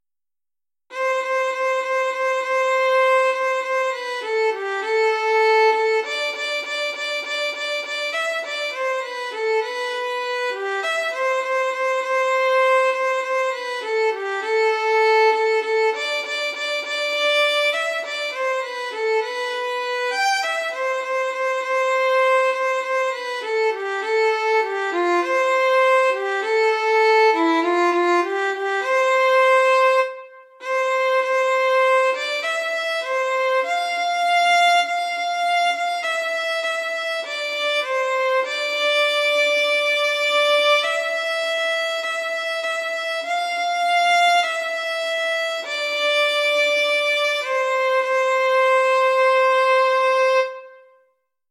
33_albemarle_st1_stgeorge_fiddle.mp3 (806.12 KB)
Audio fiddle of transcribed recording of stanza 1 of “Albemarle” ballad, sung without choral harmony to “St. George”